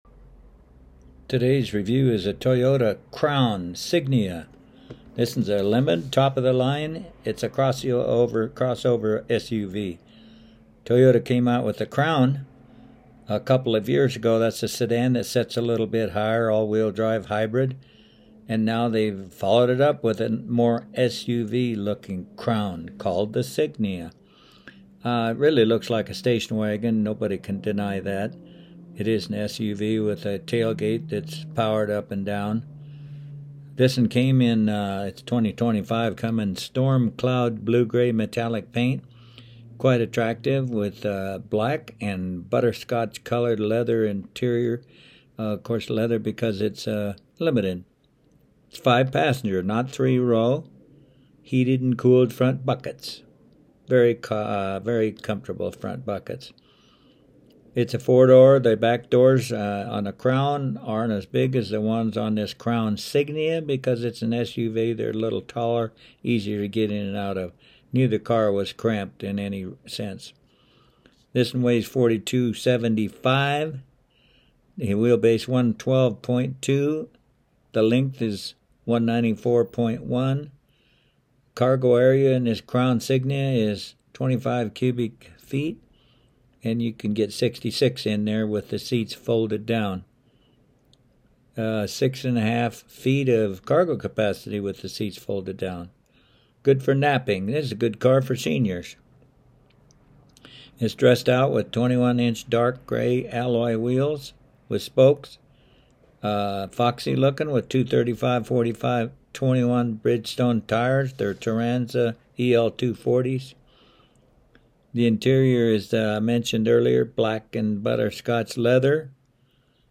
The Crown Signia was reviewed at the Pirate Radio 104.7 FM studios in Greeley: